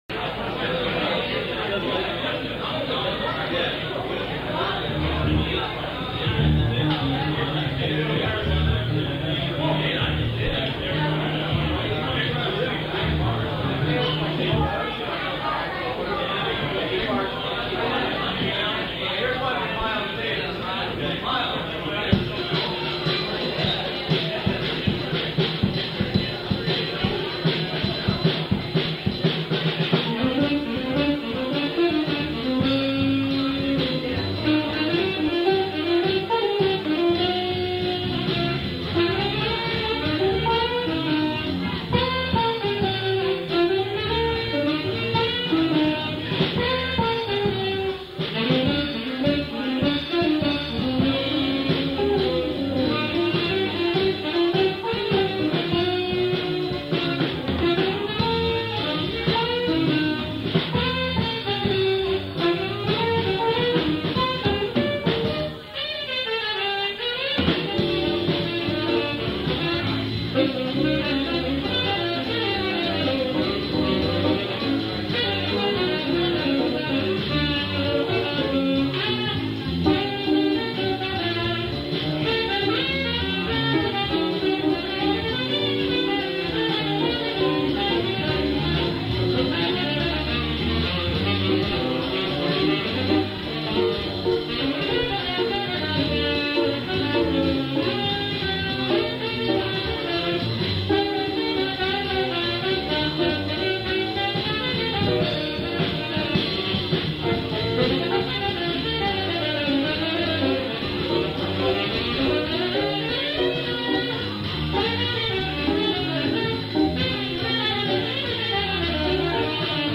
Electric Bassist in Los Angeles For Approximately Four Years
live